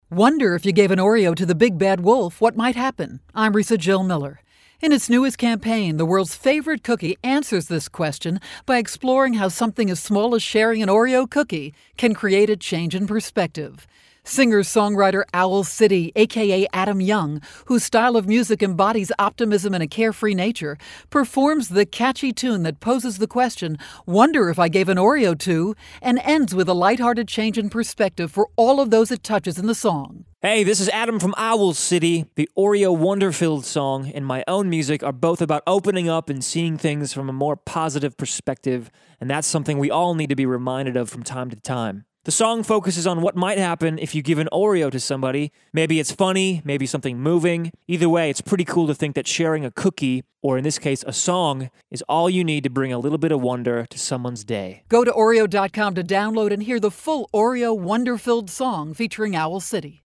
May 13, 2013Posted in: Audio News Release